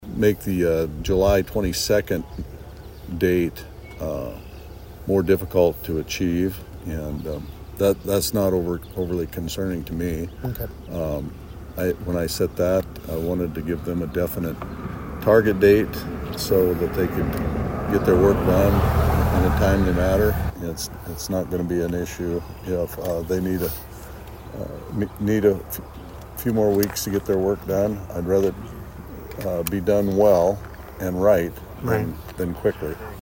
In an interview with KSDN, Governor Rhoden indicated the July 22nd date was subject to change.